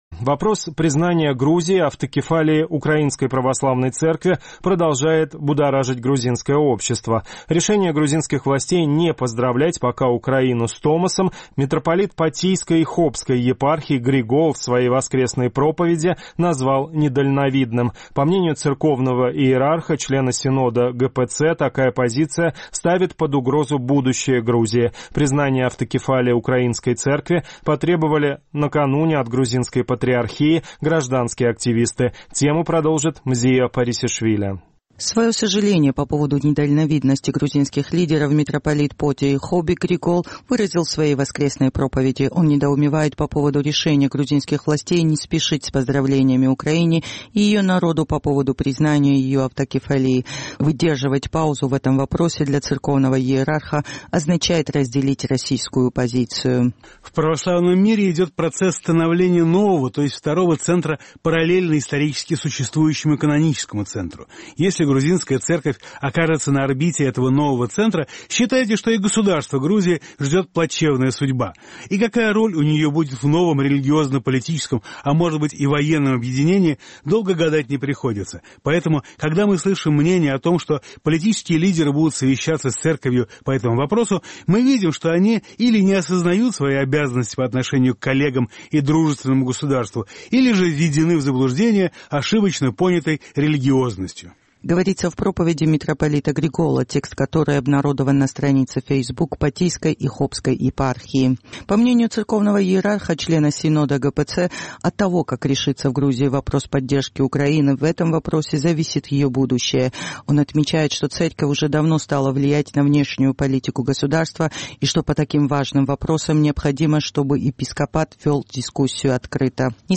Вопрос признания Грузией автокефалии УПЦ продолжает будоражить грузинское общество. Решение грузинских властей не поздравлять пока Украину с томосом митрополит Григол в своей воскресной проповеди назвал недальновидным.